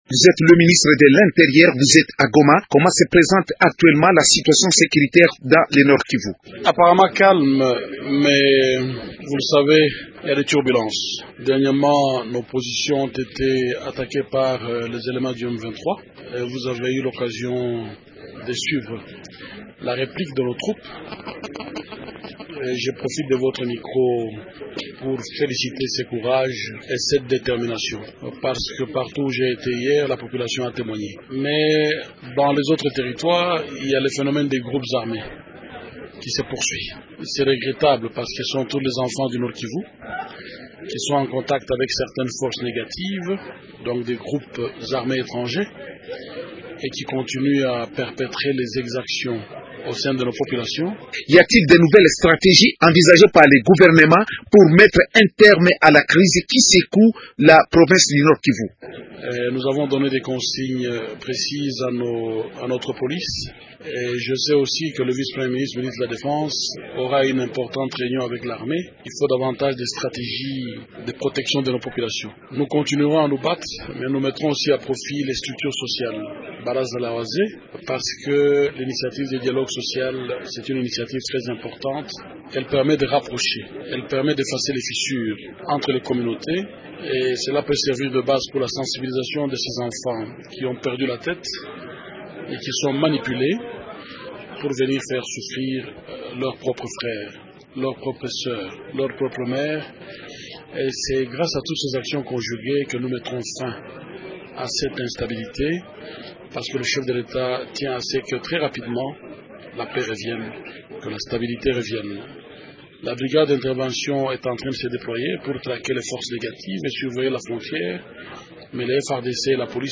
L’invité du jour ce lundi est Richard Muyej, le ministre de l’Intérieur. Dans une conférence de presse tenue dimanche 26 mai à Goma, Richard muyej a affirmé que « la situation sécuritaire reste relativement calme sur l’ensemble de la province » après les récents combats entre les rebelles du M23 et les FARDC.